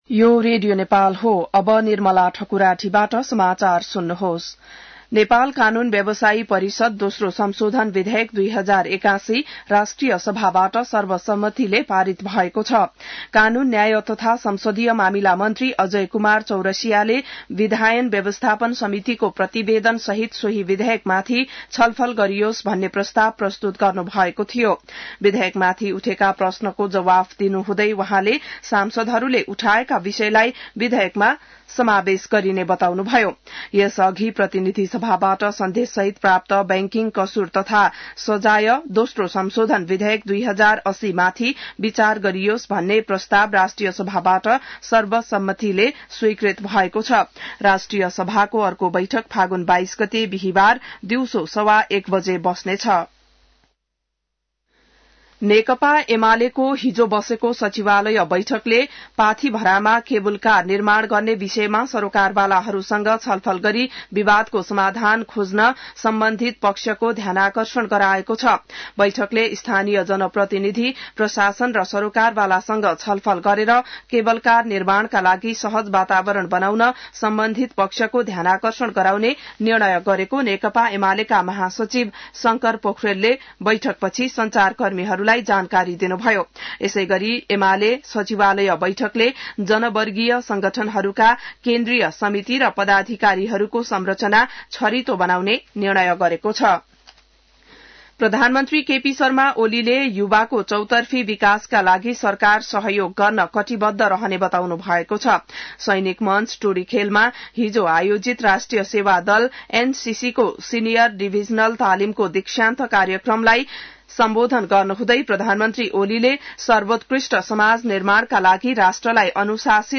बिहान ६ बजेको नेपाली समाचार : २० फागुन , २०८१